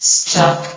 S.P.L.U.R.T-Station-13 / sound / vox_fem / stuck.ogg
CitadelStationBot df15bbe0f0 [MIRROR] New & Fixed AI VOX Sound Files ( #6003 ) ...